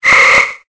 Cri de Kirlia dans Pokémon Épée et Bouclier.